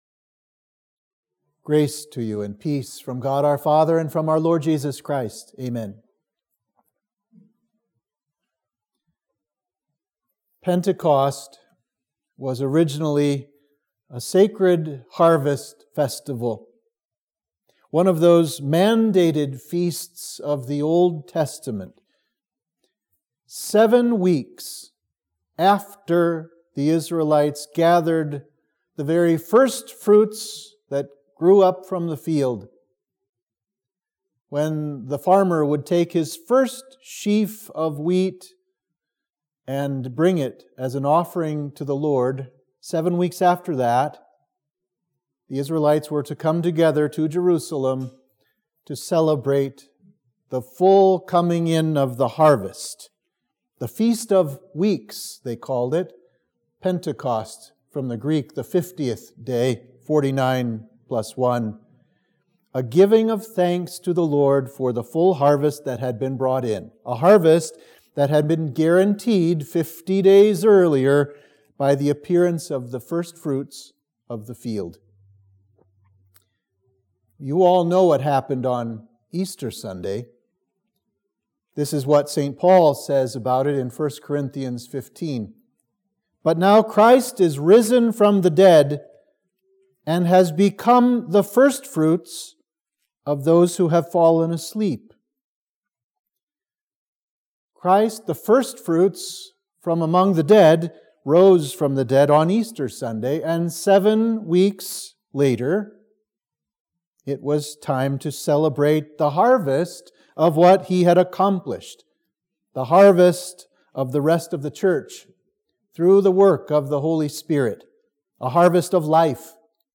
Sermon for the Festival of the Day of Pentecost